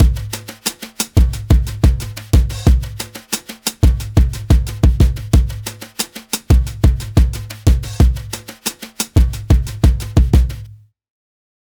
Unison Jazz - 7 - 90bpm.wav